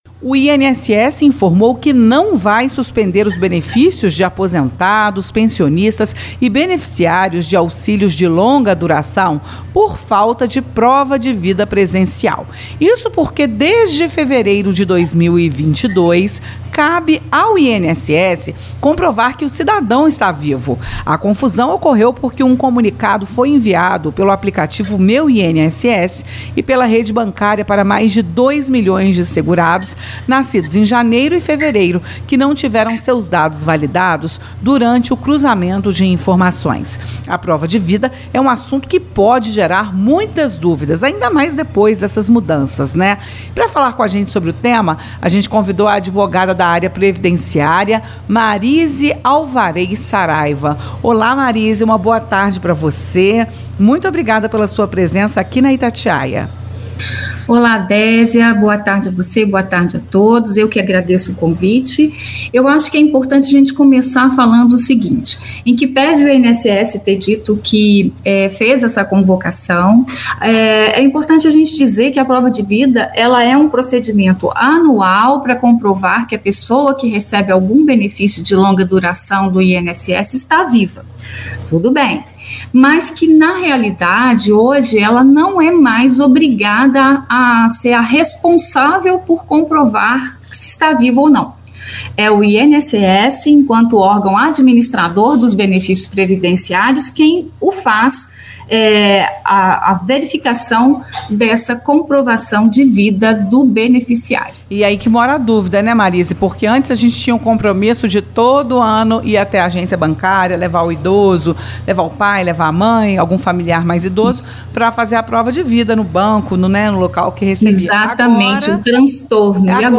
Advogada previdenciária esclarece sobre prova de vida e orienta sobre manutenção dos benefícios do INSS ao Itatiaia Entrevista